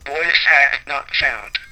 If you want to hear both the old and current ones (recorded with my phone), here are some of them (each words will play twice):